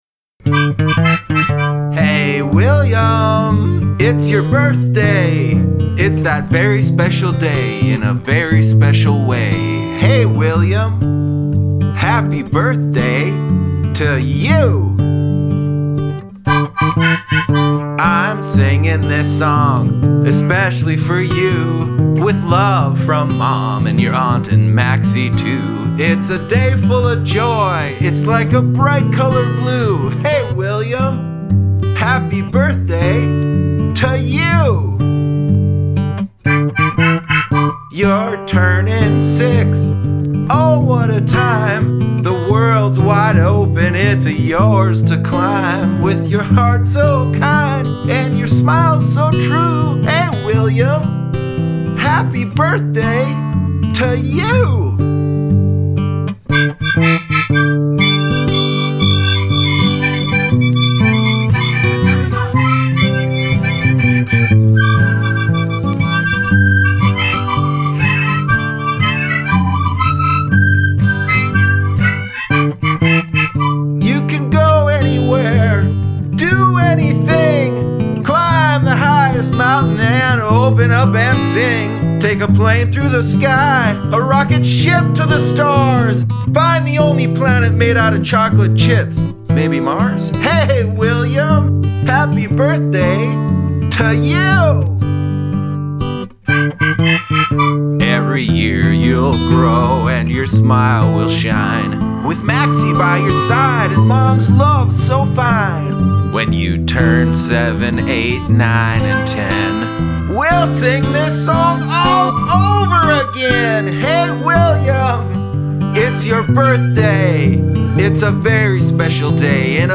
– Finished Studio Recording:
I’d like it to be a straightforward song with just your voice and an instrument you play.